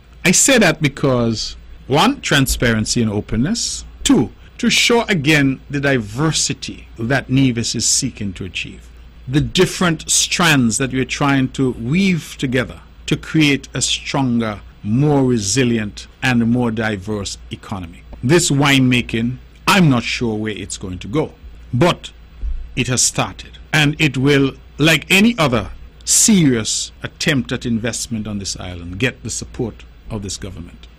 In providing more information, this is the voice of Premier Hon. Mark Brantley: